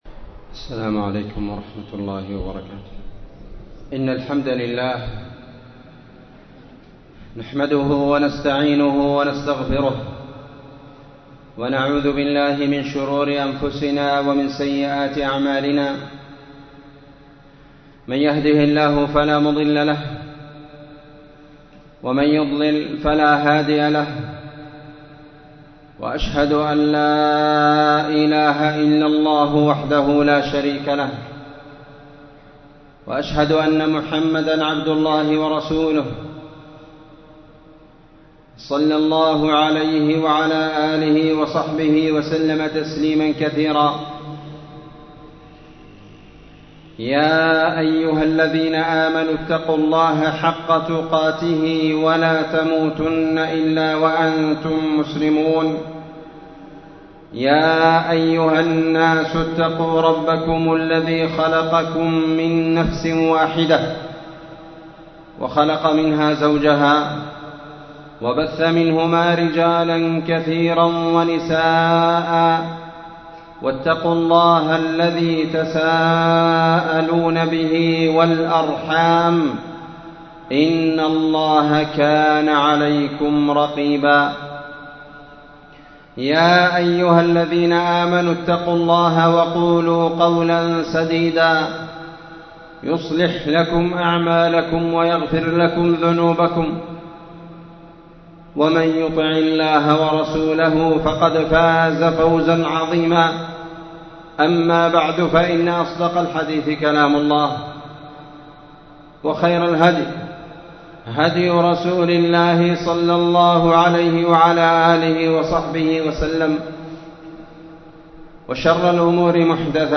خطبة الجمعة
مسجد المجاهد مسجد أهل السنة والجماعة تعز _اليمن